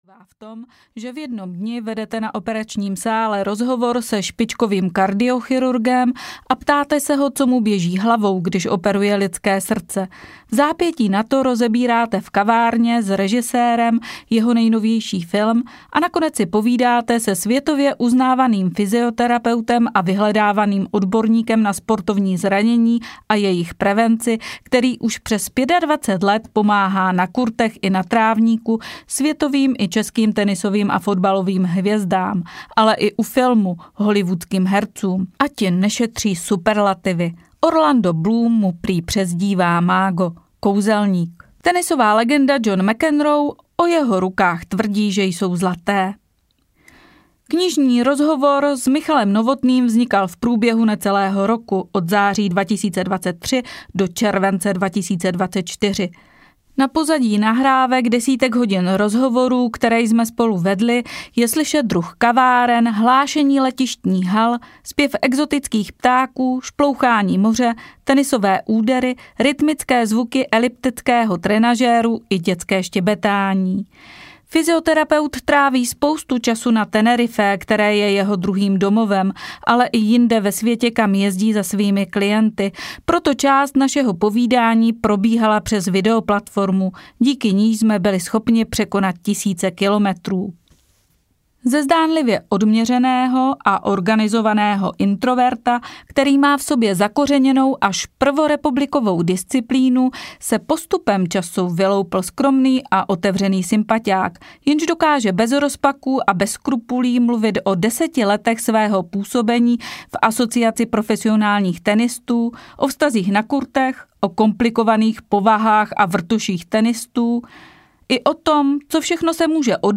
Tajemství zdravého pohybu audiokniha
Ukázka z knihy